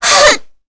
Kara-Vox_Damage_kr_03.wav